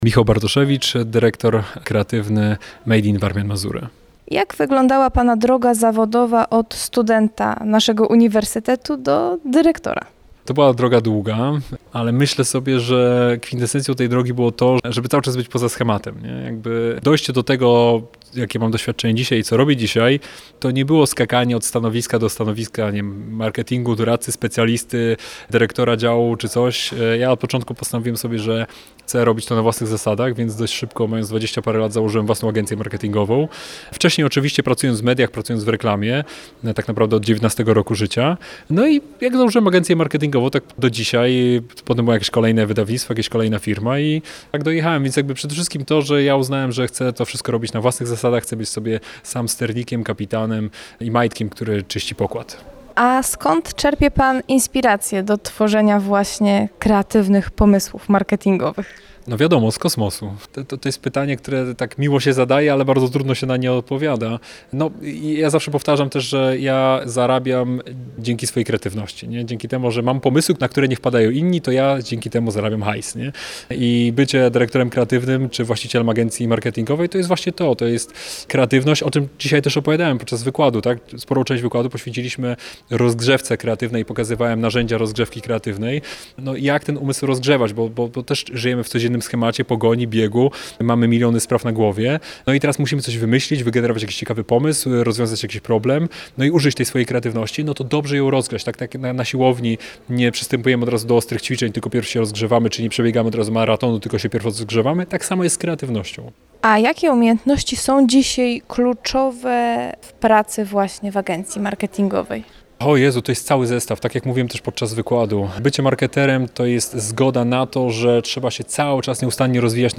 Studentom dziennikarstwa i komunikacji społecznej przedstawiał możliwości rozwoju zawodowego. Spotkała się z nim także nasza reporterka.
– mówił naszej reporterce. I dodał: